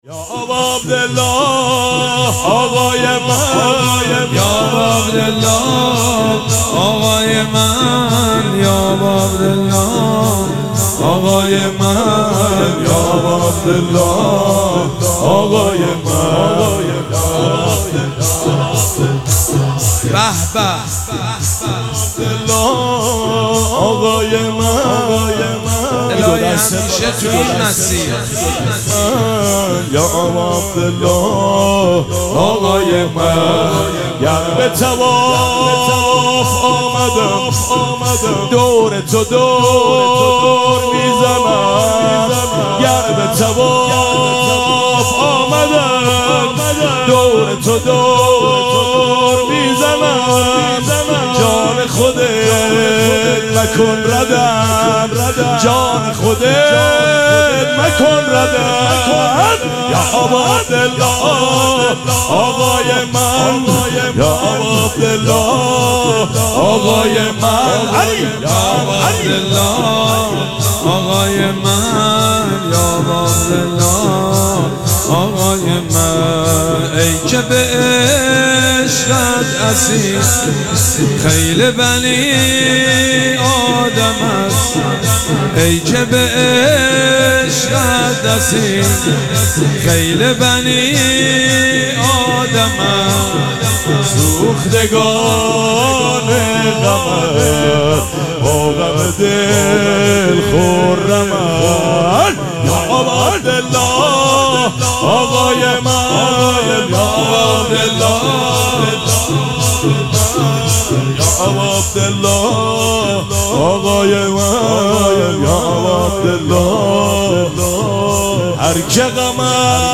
شب سوم مراسم عزاداری اربعین حسینی ۱۴۴۷